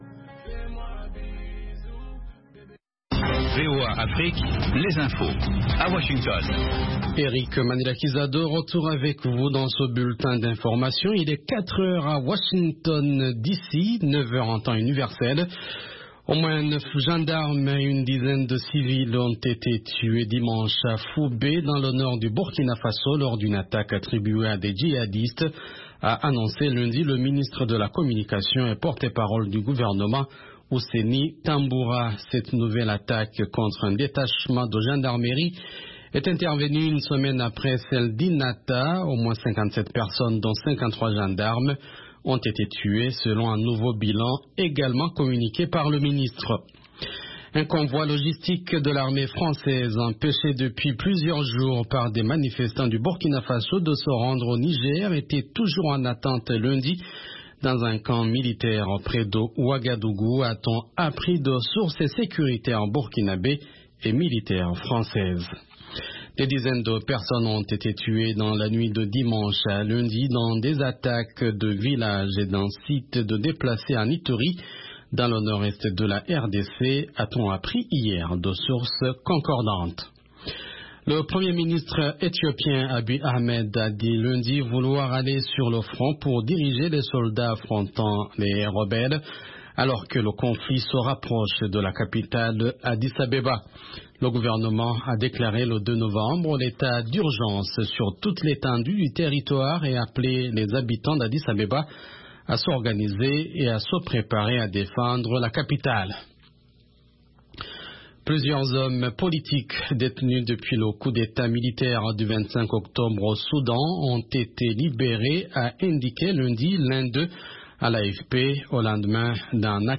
5 min Newscast